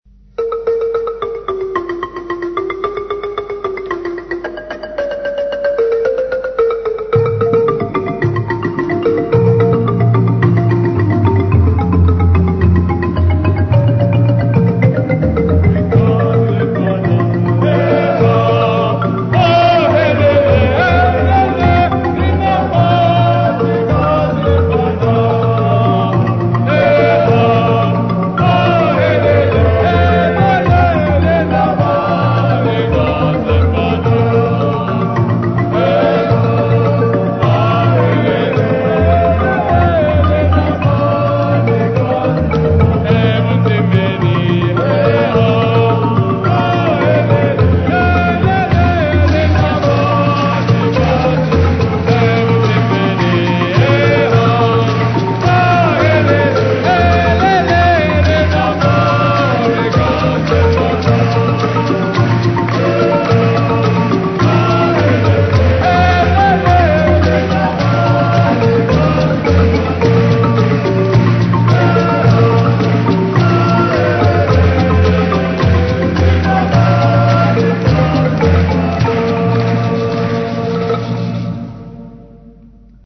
Marimba Group
Folk music
Field recordings
Church hymn, accompanied by the Marimba, rattles and clapping.
Studer B67 Tape Recorder